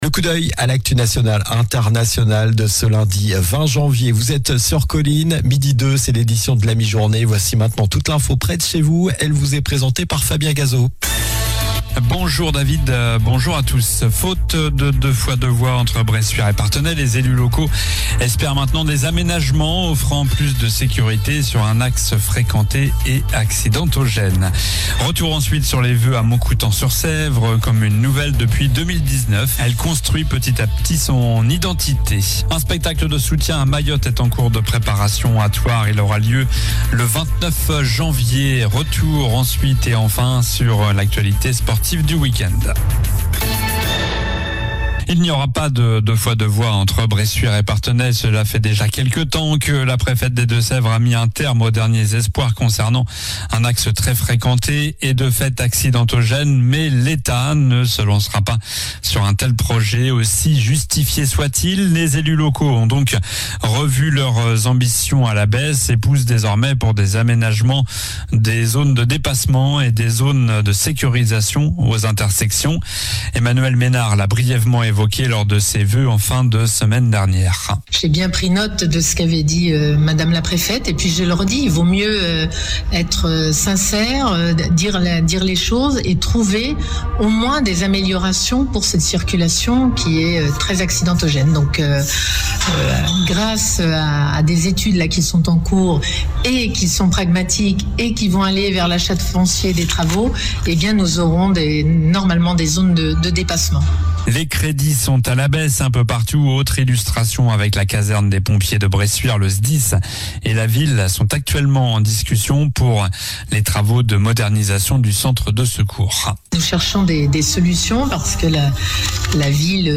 Journal du lundi 20 janvier (midi)